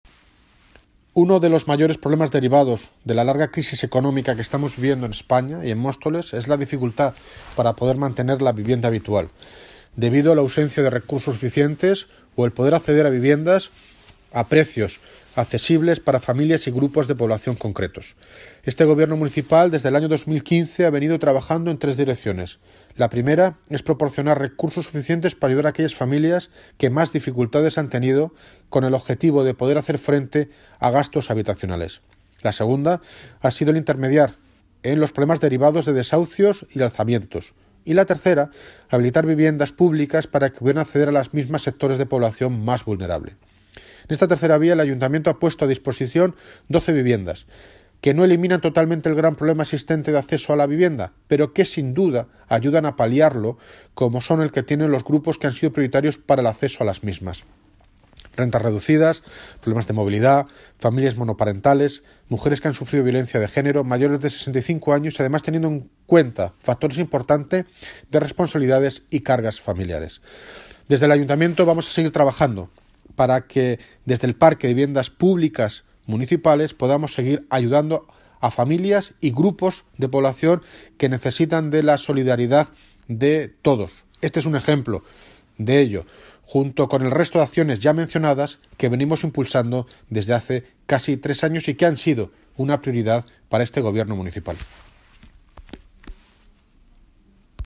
Audio - David Lucas (Alcalde de Móstoles) Sobre entrega viviendas IMS